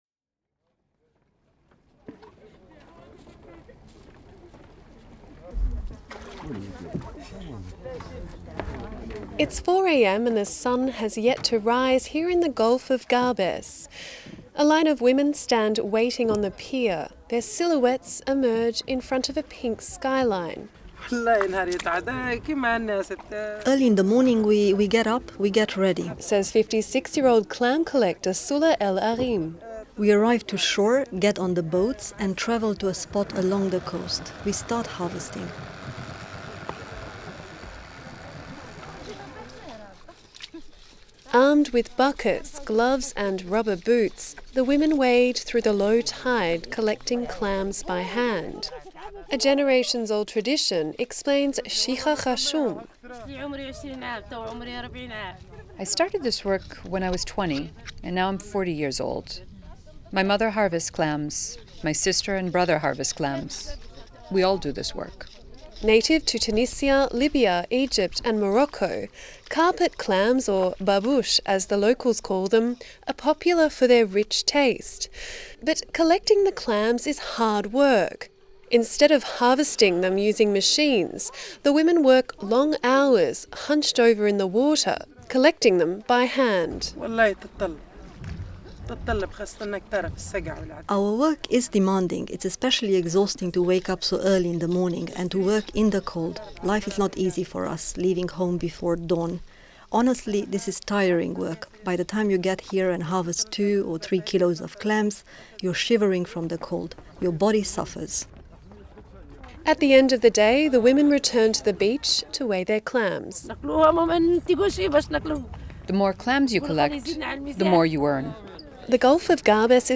Presenter: